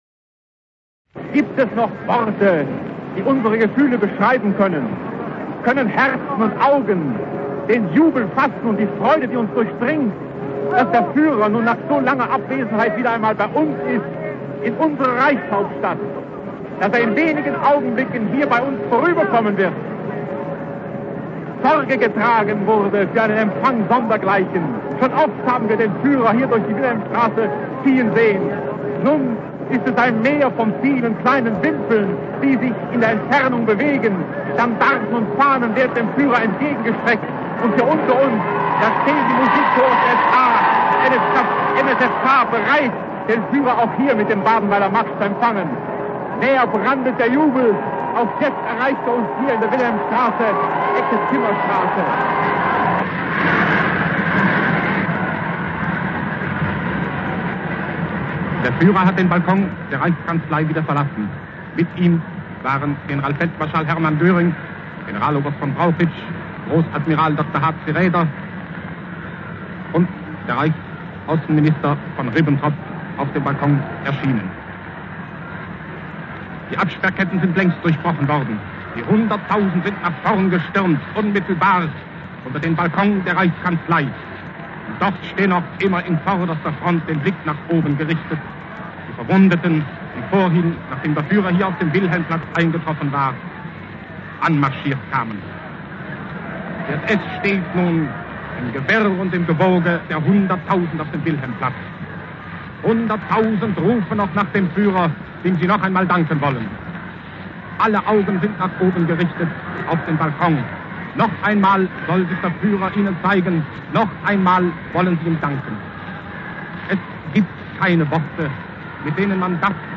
Treffpunkt der Interessengemeinschaft :: Thema anzeigen - Rundfunkreportage vom 06.07.1940
Wir hören 3 Rundfunkreporter, die am 06. Juli 1940 über den Einzug Hitler's in Berlin, nach dem Sieg über Frankreich, berichten.
Die RauschUnterdrückung ist minimal, damit keine Artefakte entstehen. Die kräftigen Rumpelgeräusche sind kein Produkt meiner Aufnahme, sie sind Originalton. Man erkennt es an dem kurzen Ab- und Aufblenden beim 2. Reporter, In der kurzen Pause ist lediglich das Vinylrauschen zu "hören".